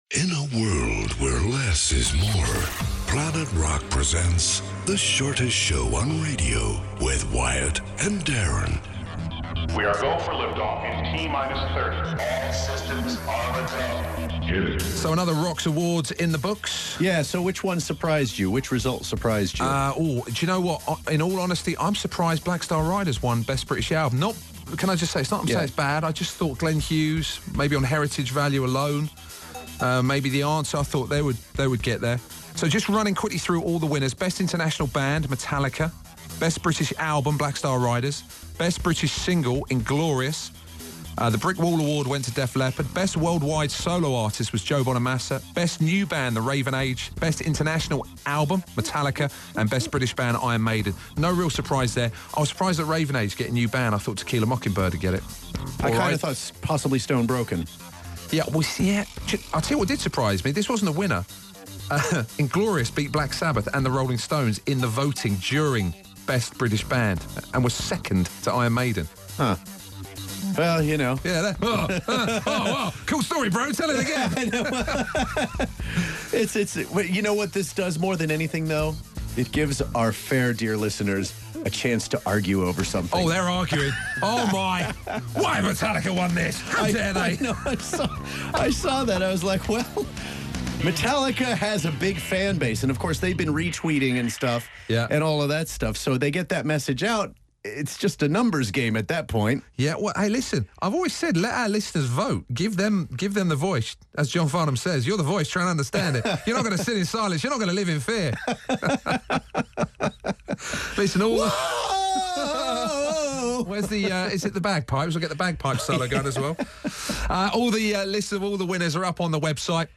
and engage in a terrible bit of singing...